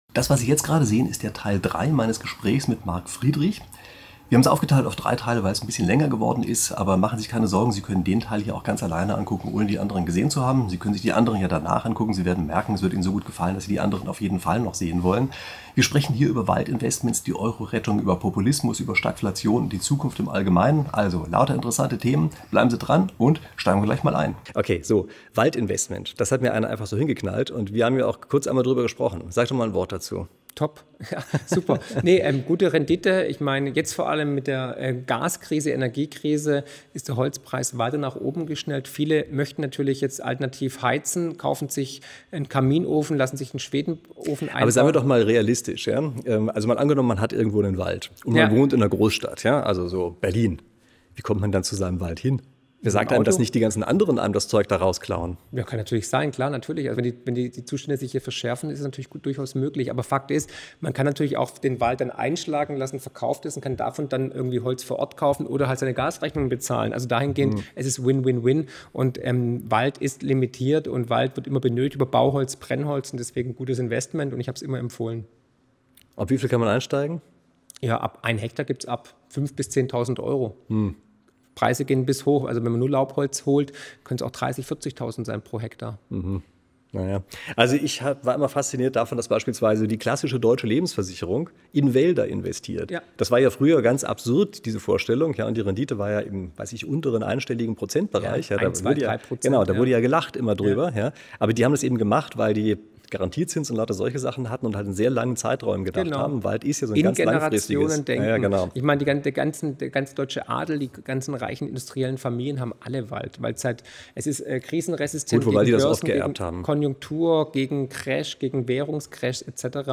Speed-Interview